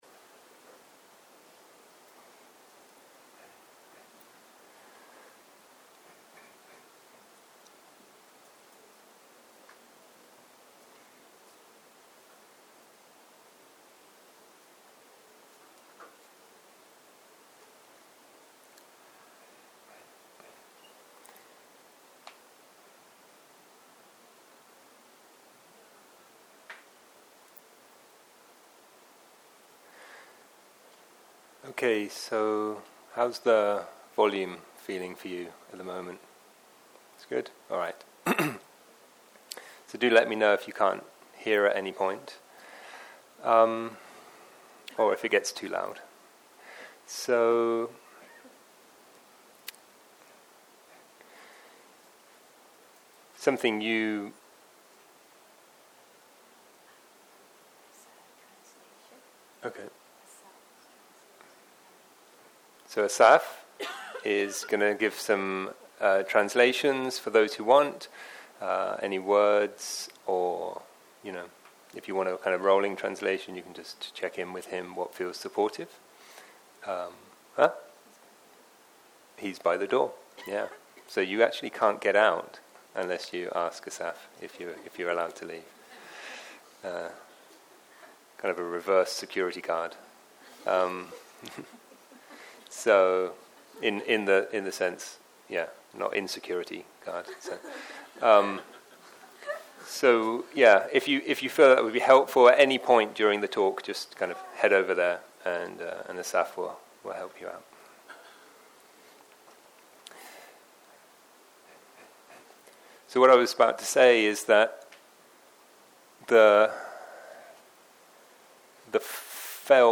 ערב - שיחת דהרמה - Reality of perception
סוג ההקלטה: שיחות דהרמה